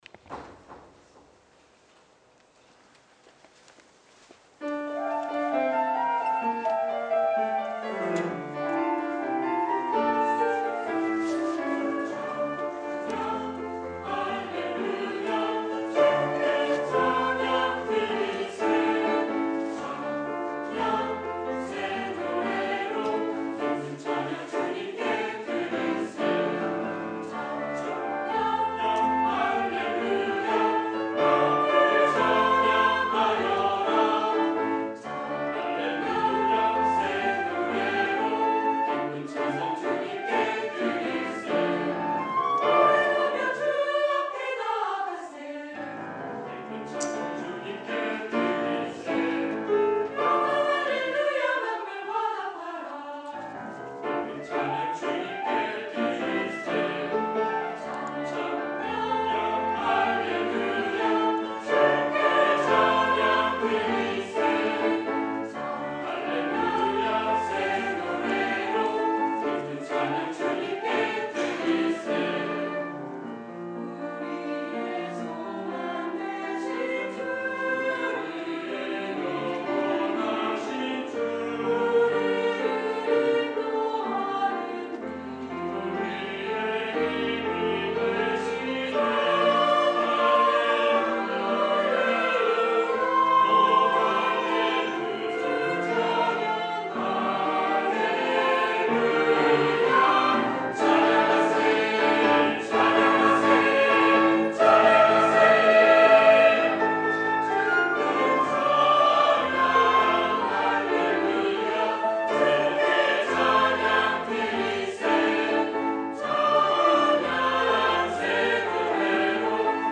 주일찬양